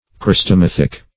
Search Result for " chrestomathic" : The Collaborative International Dictionary of English v.0.48: Chrestomathic \Chres`to*math"ic\, a. Teaching what is useful.